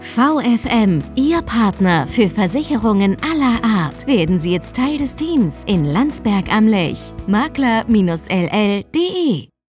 Funkspot_vfm_Bauer_Kollegen_GmbH_10_Sek.mp3